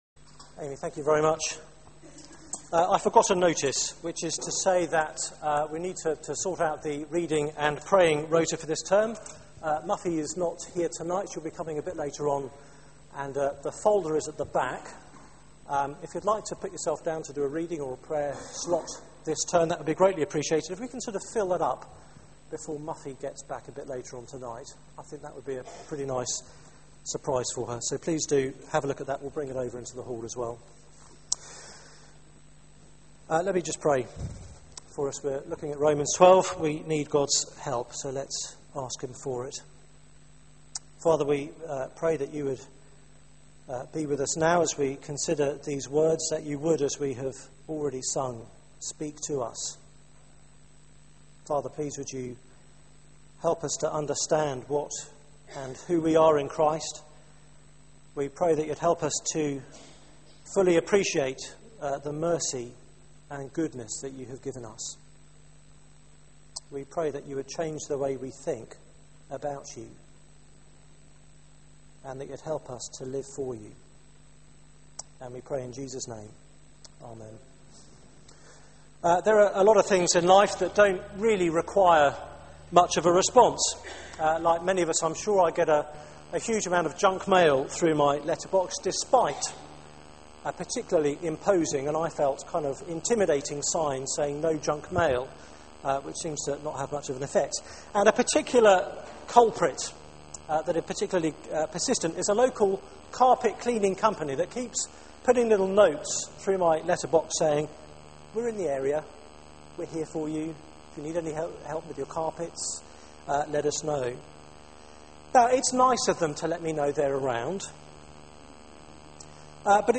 Media for 6:30pm Service on Sun 02nd Sep 2012 18:30 Speaker
Passage: Romans 12:1-2 Series: The Christian Life Theme: True worship Sermon